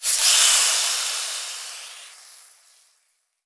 cooking_sizzle_burn_fry_03.wav